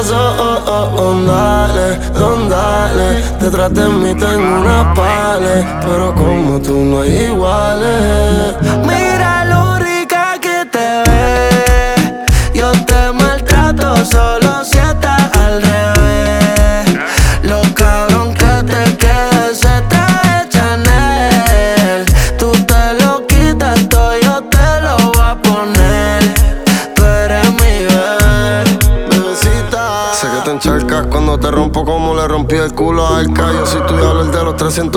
# Latin Urban